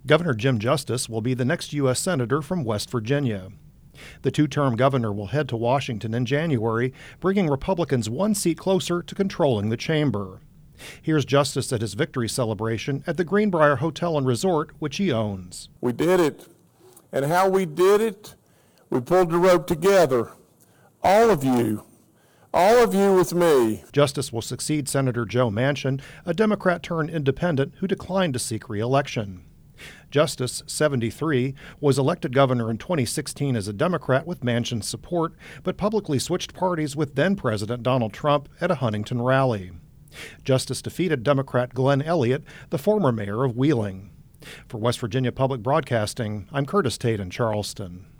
Justice spoke at his victory celebration at the Greenbrier Hotel and Resort, which he owns.